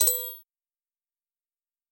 Короткий звуковой сигнал